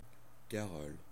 Ääntäminen
Synonyymit déambulatoire Ääntäminen France (Normandie): IPA: [ka.ʁɔl] Haettu sana löytyi näillä lähdekielillä: ranska Käännöksiä ei löytynyt valitulle kohdekielelle.